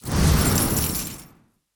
victory_reward_fly.ogg